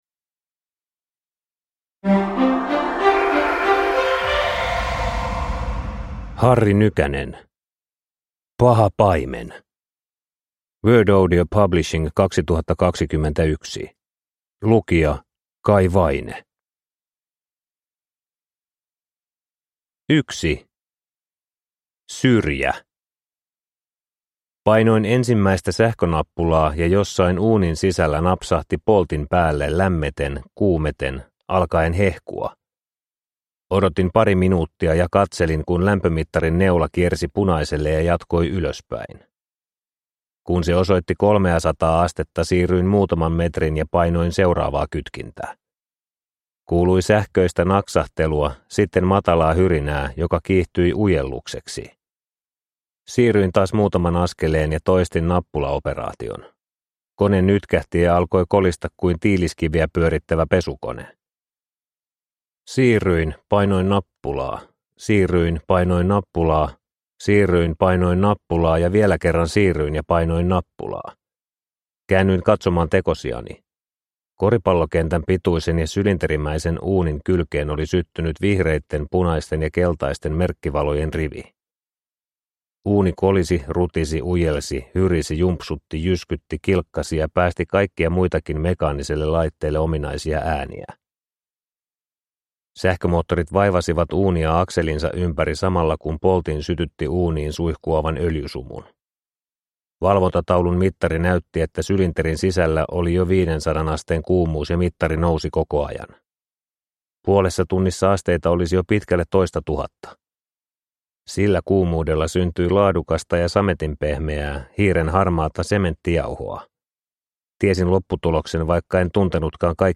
Paha paimen – Ljudbok – Laddas ner